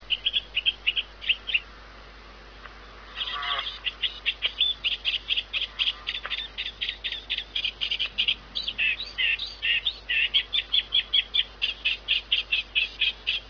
Reed Warbler. The bird of Knutsford Moor - breeds in good numbers and it's arrival is eagerly awaited each Spring, compare it's song with the one below
reed.ra